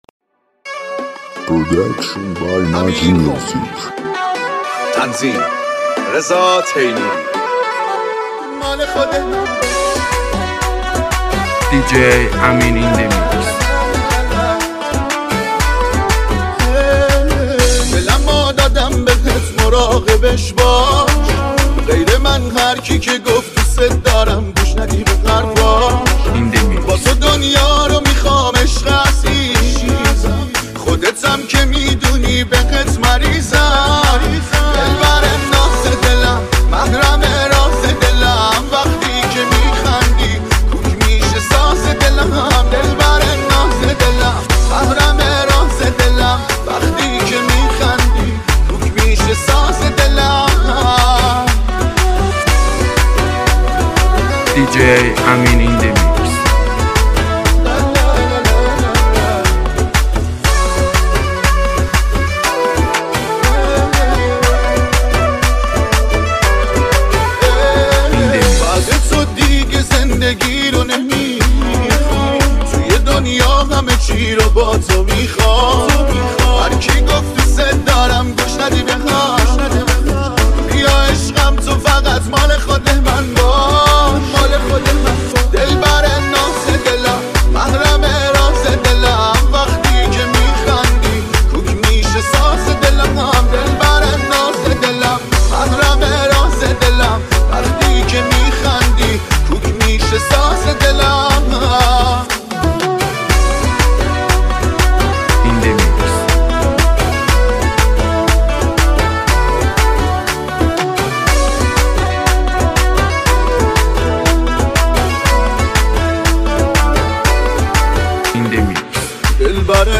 شاد تریبال
تند بیس دار سیستمی مخصوص ماشین
تریبال شاد بیس دار تند